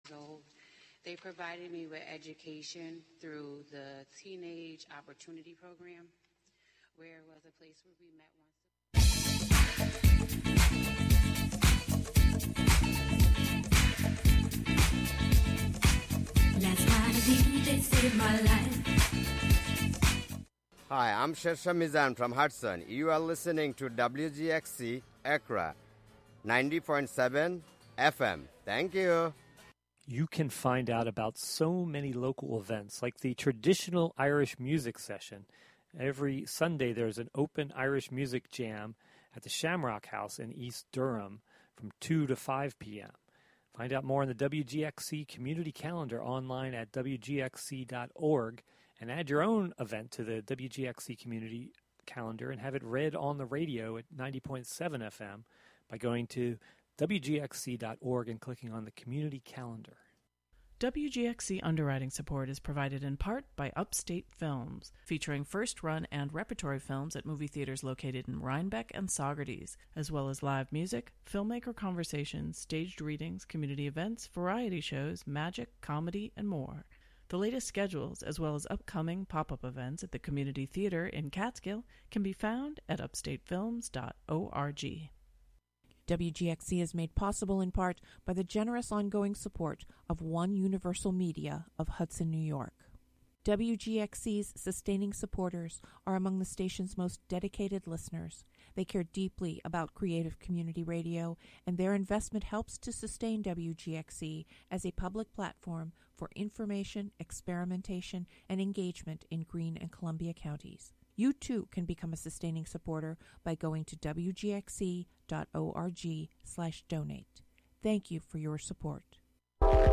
View Image Gallery broadcasts Mountains : Sep 19, 2023: 10am - 11am Sounds, recordings, and music inspired by a hike i...
The show features music, field recordings, performances, and interviews, primarily with people in and around the Catskill Mountains of New York live from WGXC's Acra studio.